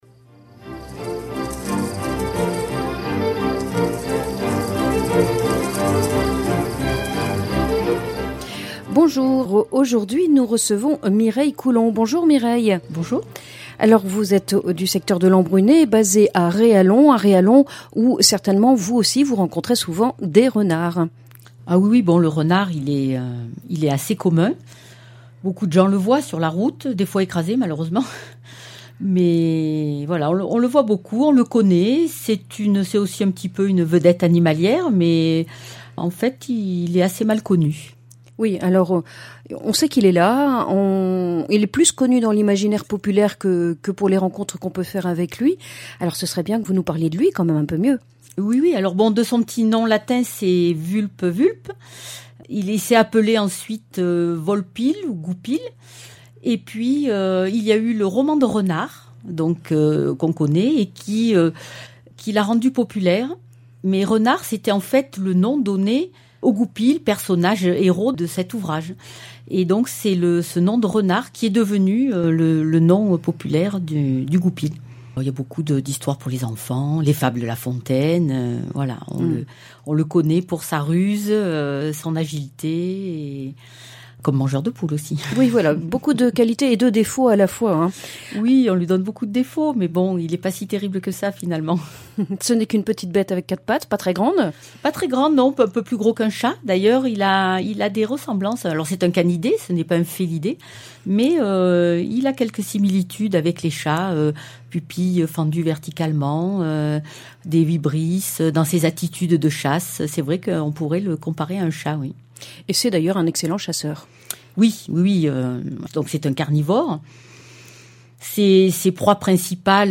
Chronique nature Qui ne connaît pas le renard ?